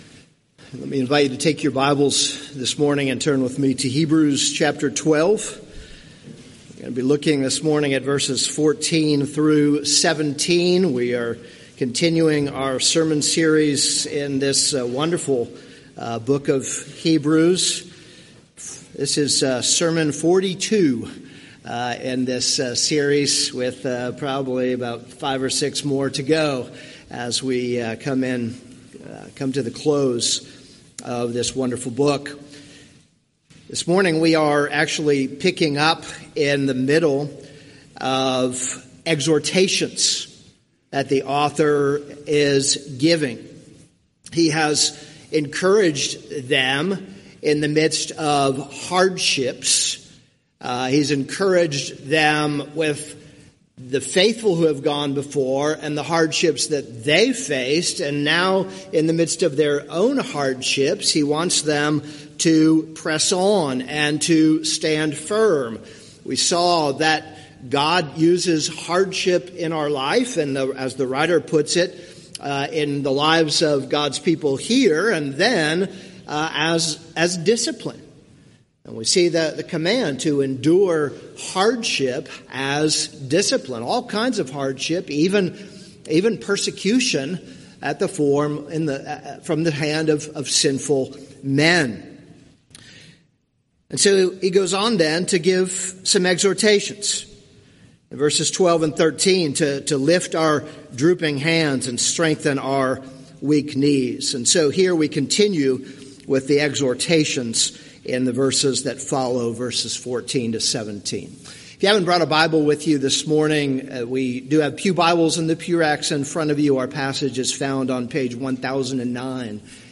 This is a sermon on Hebrews 12:14-17.